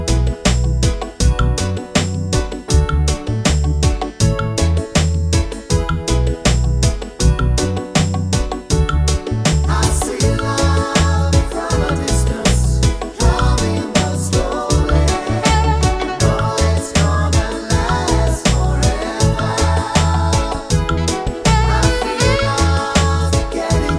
no Backing Vocals Reggae 3:52 Buy £1.50